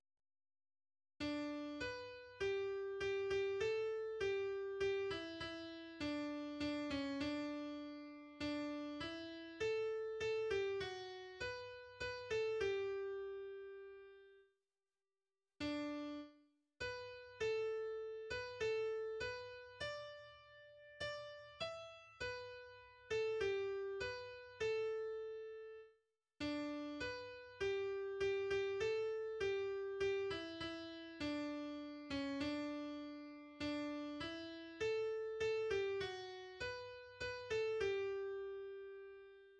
\version "2.12.3" \language "deutsch" \header { tagline = "" } \layout { indent = #0 } akkorde = \chordmode { \germanChords \set chordChanges = ##t } global = { \autoBeamOff \tempo 4 = 100 \time 3/4 \key g \major } melodie = \relative c' { \global r4 r d \repeat volta 2 { h'4 g( g8) g a4 g( g8) e e4 d( d8) cis d2 d4 e4 a( a8) g fis4 h( h8) a g2.